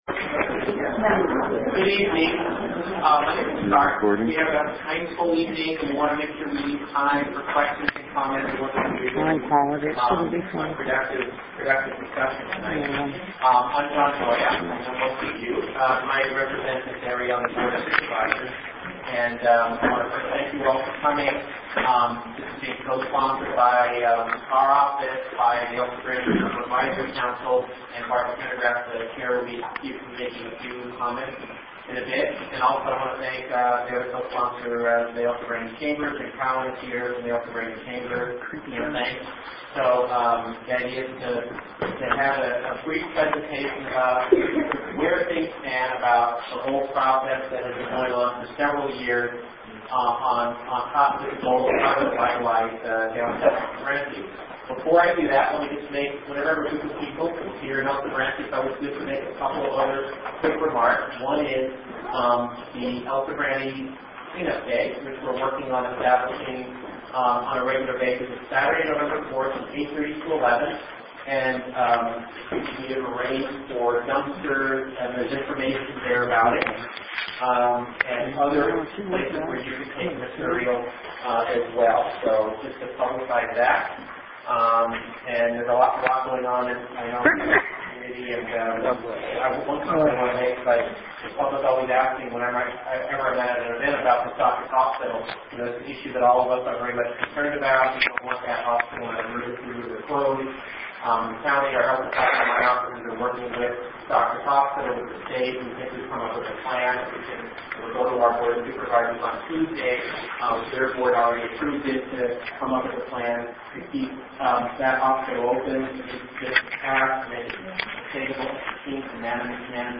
El Sobrante General Plan Forums, Oct. 26 & 28, 2006 MEETING NOTICE Audio of Thursday Oct. 26 meeting held at Salvation Army on Appian Way MAP of General Plan Area Large Scale MAP of General Plan Area